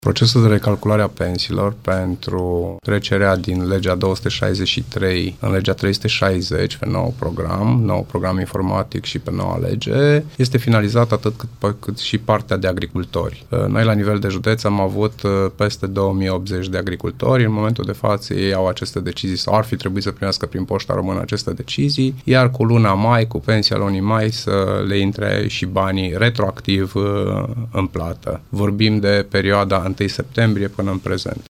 Drepturile bănești vor fi achitate retroactiv din luna mai, spune directorul Casei de Pensii Timiș, Cristian Mircea.